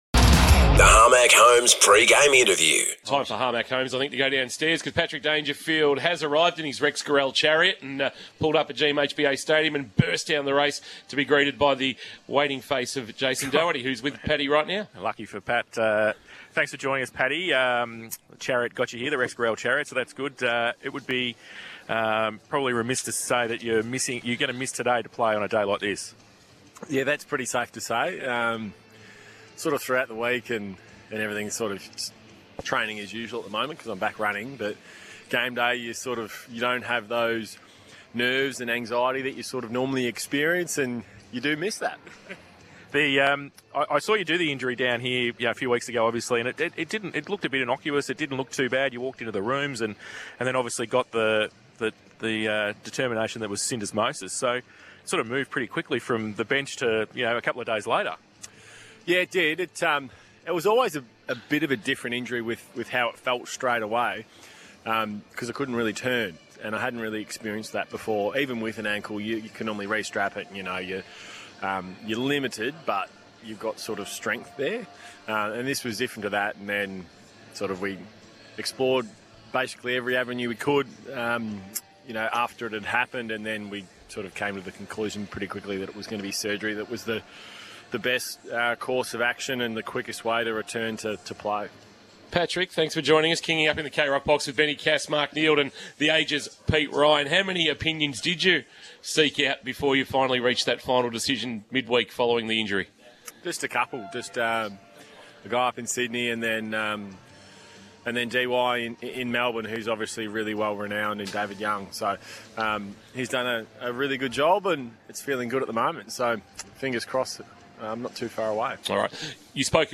PRE-MATCH INTERVIEW: PATRICK DANGERFIELD - Geelong